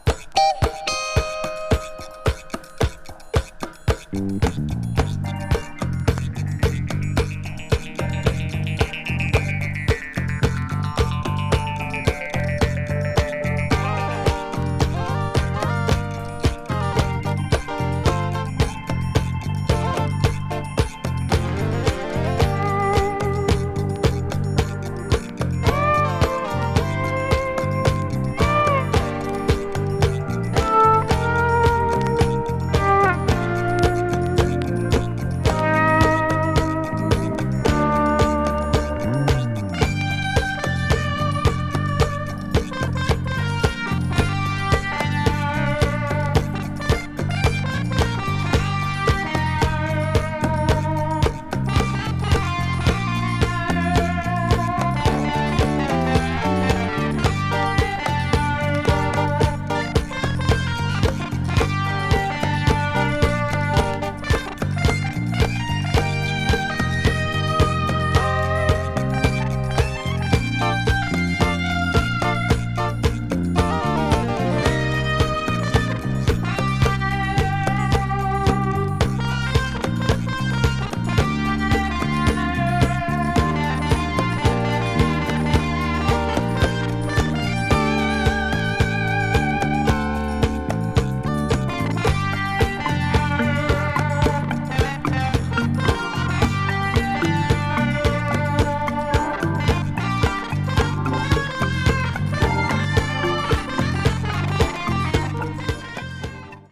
コンディション　Vg++/Vg++　A面の盤面にくすみがありますが、音には影響ありません